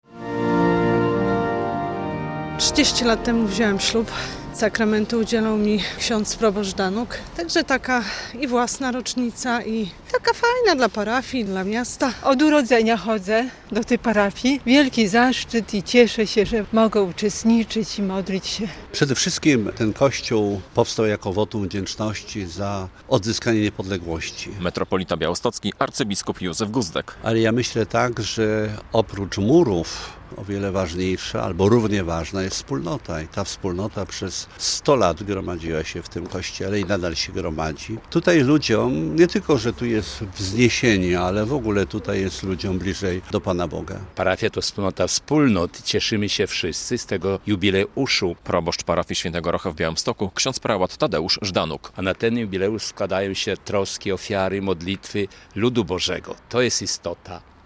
Przed mszą w rozmowie z Polskim Radiem Białystok abp Józef Guzdek podkreślił, że kościół powstał jako wotum wdzięczności za odzyskanie niepodległości.